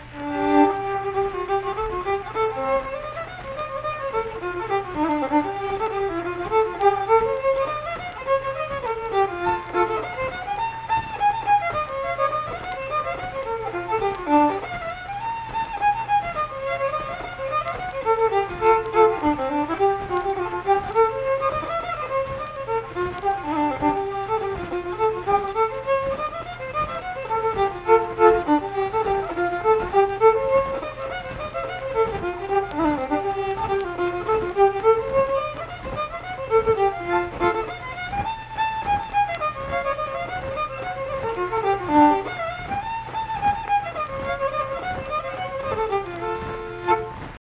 Unaccompanied vocal and fiddle music
Instrumental Music
Fiddle